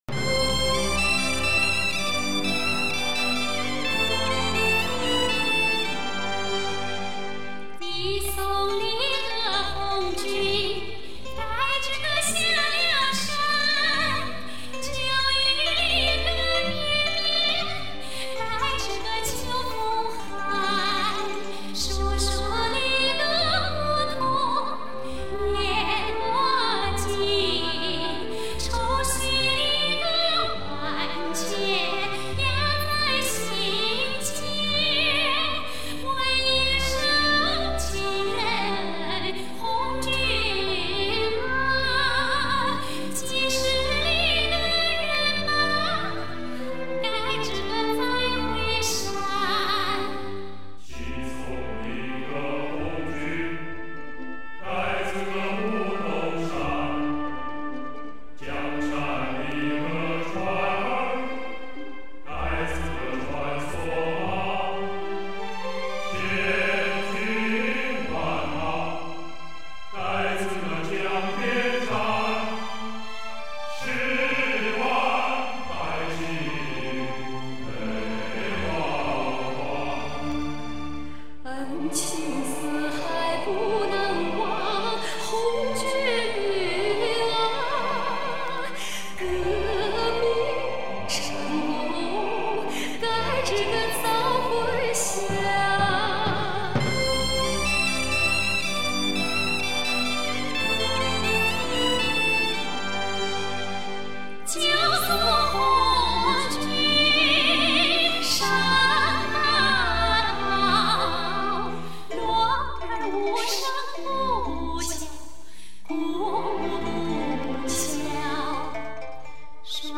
(江西民歌)